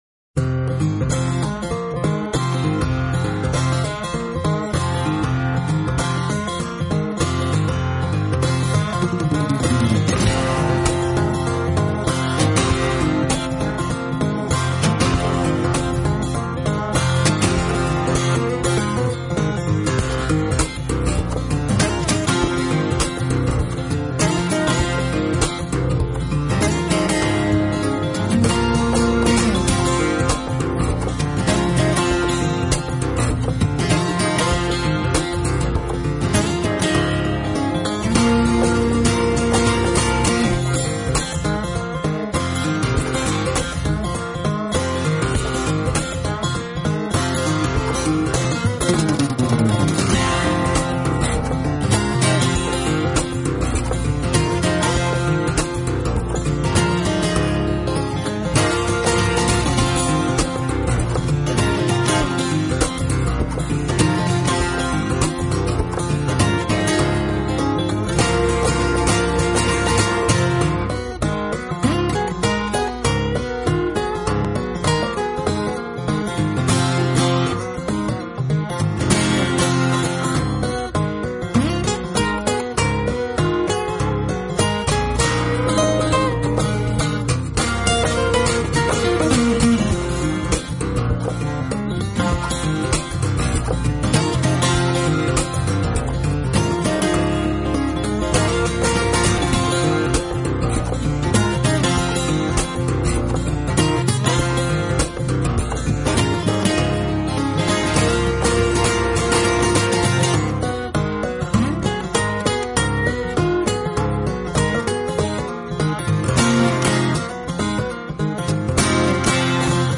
【指弹吉他】
两位大师合作的双吉他演奏专辑！